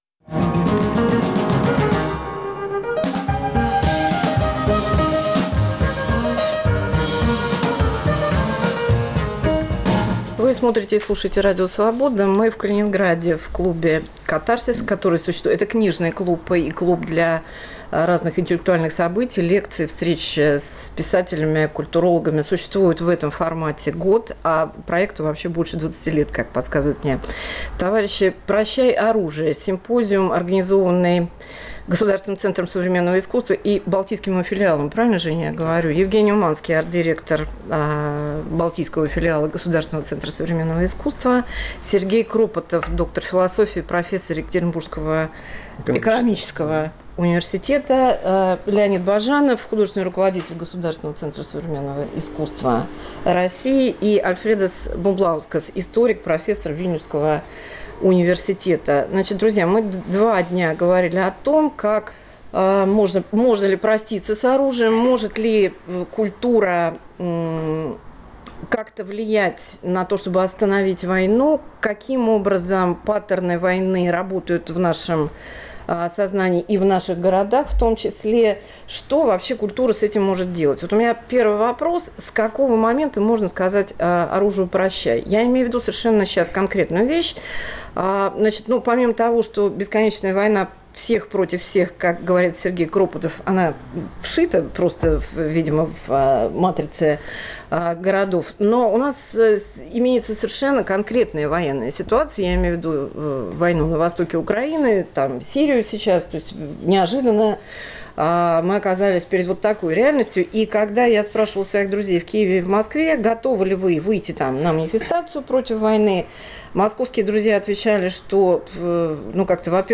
Может ли культура победить войну? Международный семинар "Прощай, оружие" в Балтийском филиале Государственного Центра Современного Искусства.